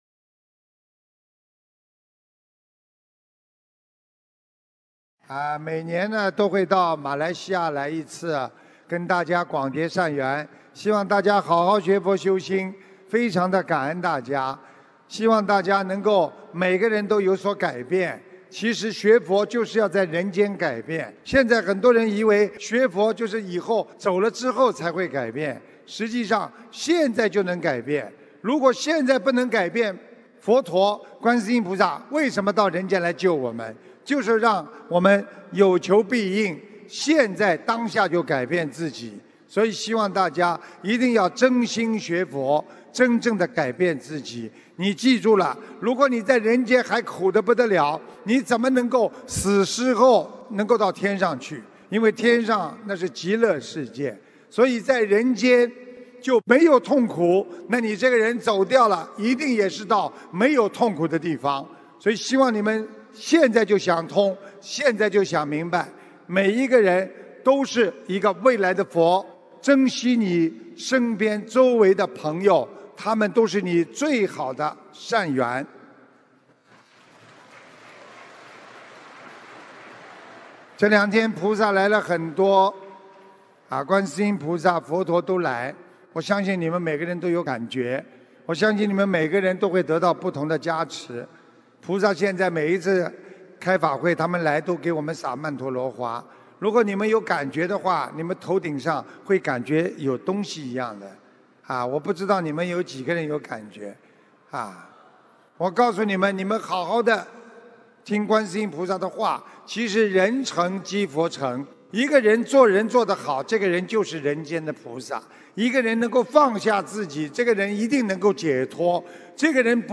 2019年10月20日马来西亚吉隆坡法会感人结束语-经典开示节选